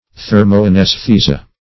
Meaning of thermoanaesthesia. thermoanaesthesia synonyms, pronunciation, spelling and more from Free Dictionary.